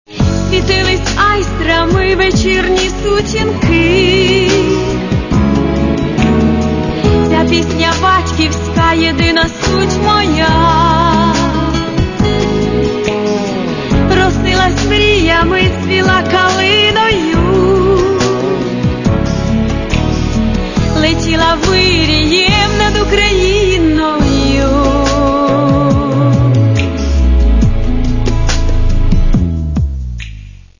Каталог -> Эстрада -> Поэты и композиторы